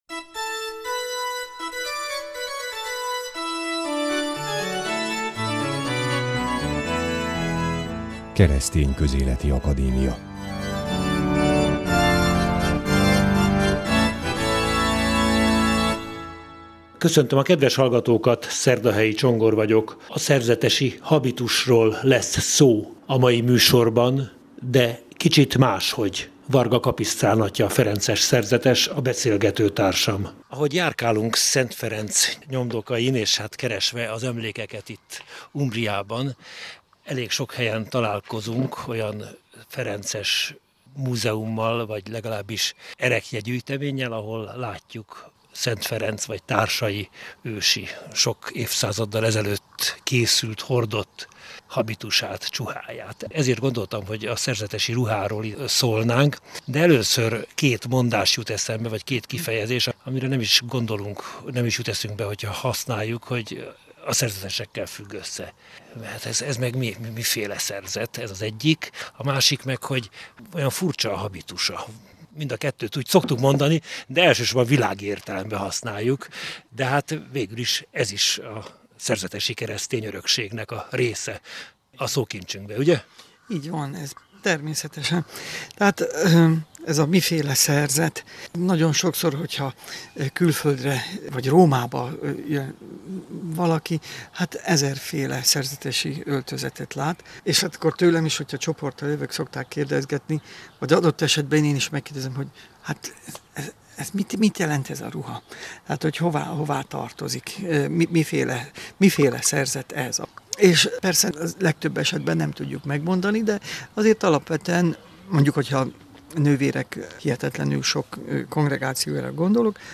A műsor itt meghallgatható.